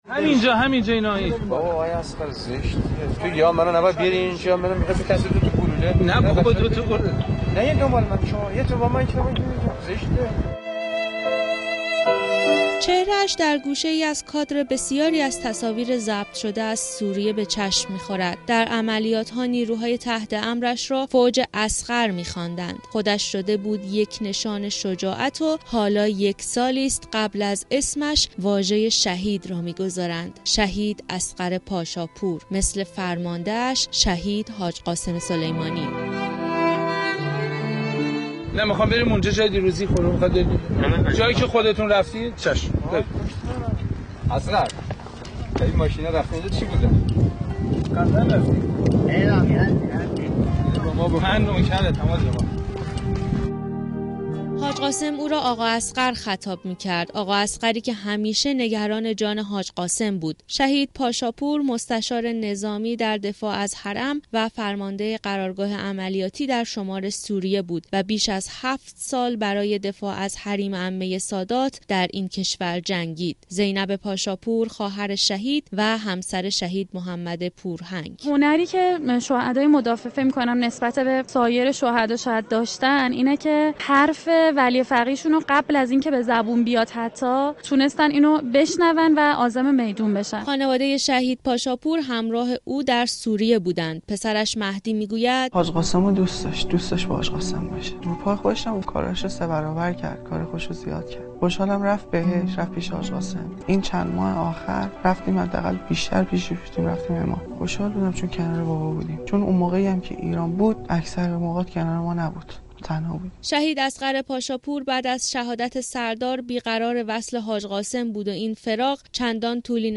گزارش خبرنگار رادیو زیارت را بشنوید: